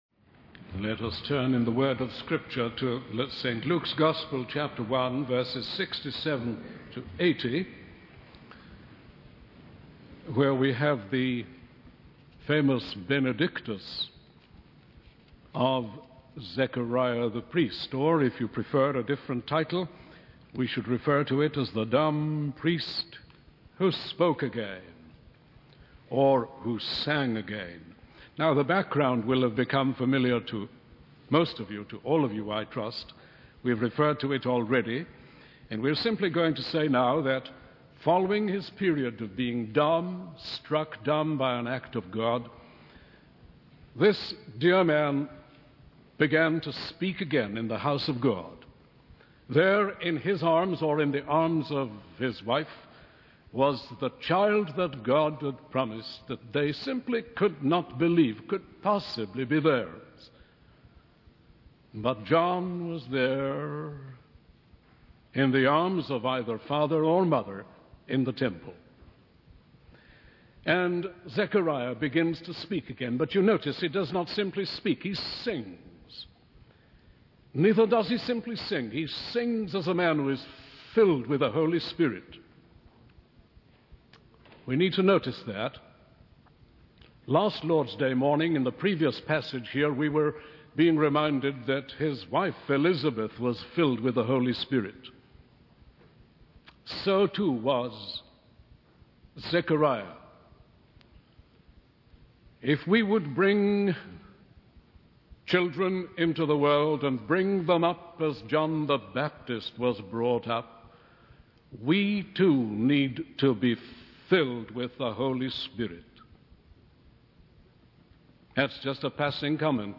In this sermon, the speaker discusses the prevailing conditions of desolation and darkness in the world during the time of Zechariah. He emphasizes the need for salvation from enemies and the importance of serving God without fear. The speaker also highlights the concept of divine visitation, where God visits his people both in grace and judgment.